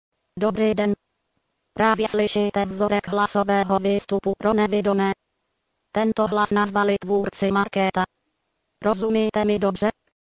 Hlasov� synt�zy